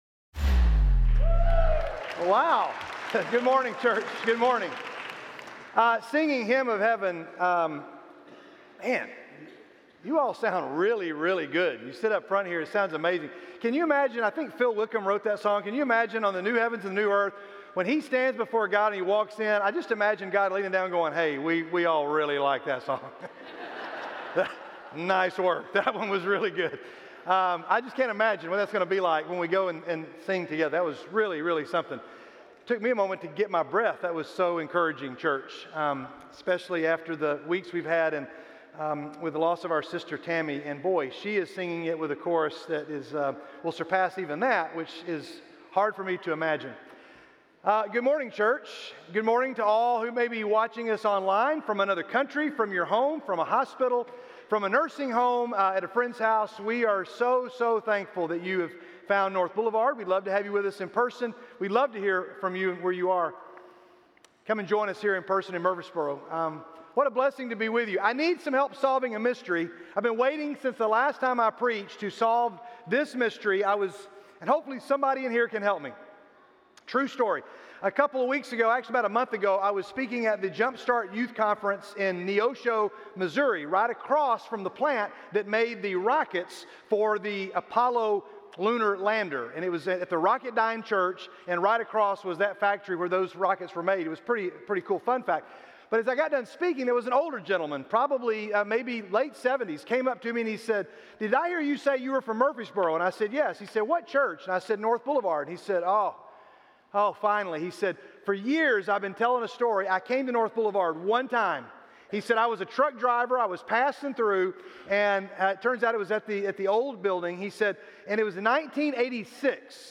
Title 00:00 / 01:04 All Sermons SERMON AUDIO GATHERING Audio download audio download video Download Video Video Also on What Does This Mean?